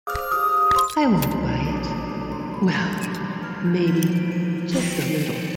Voice Line Animated